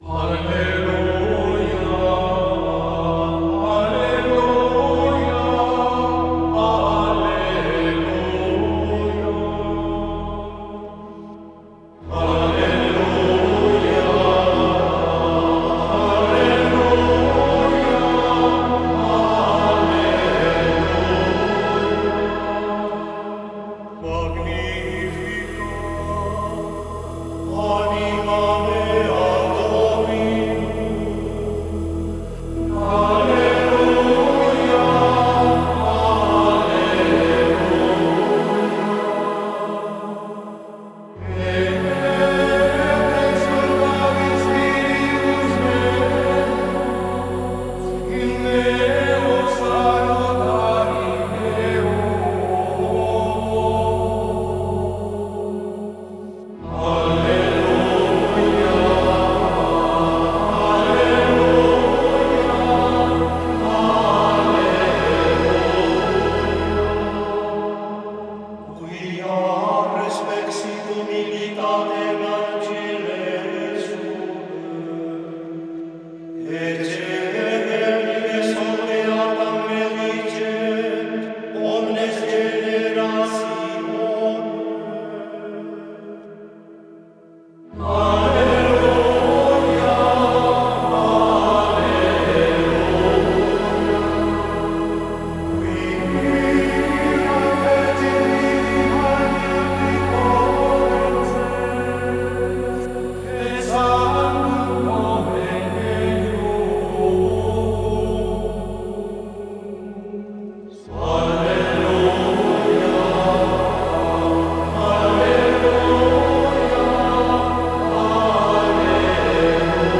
Playlist da Toca Cantos gregorianos...
Inspiring-Gregorian-Chants-MP3.mp3